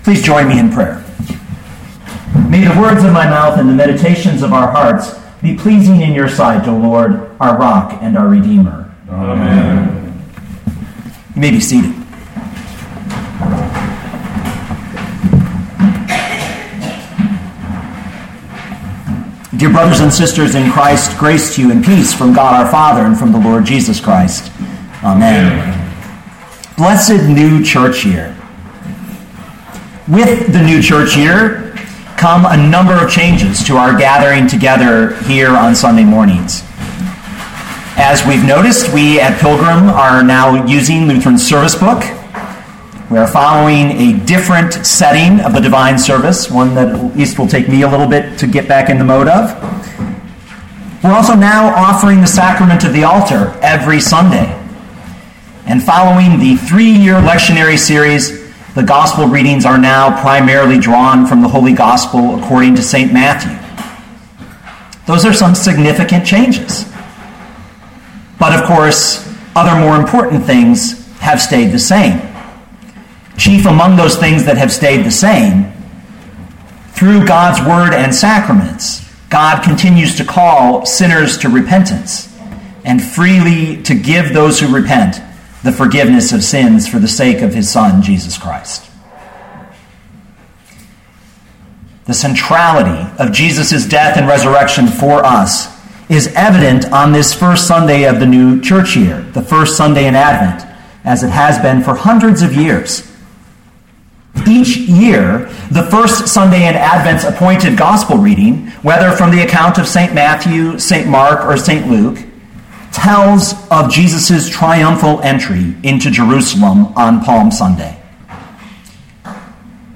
2013 Matthew 21:1-11 Listen to the sermon with the player below, or, download the audio.